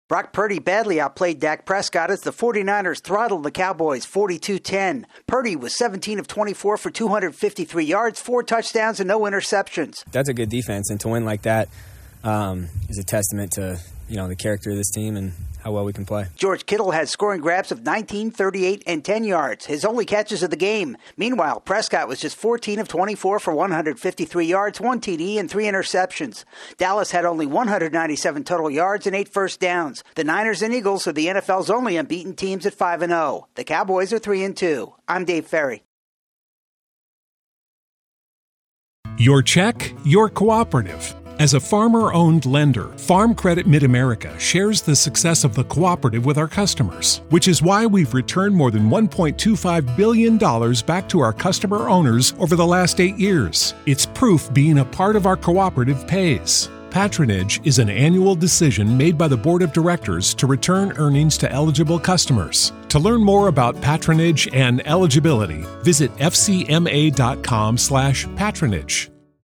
The 49ers stomp all over the Cowboys. AP correspondent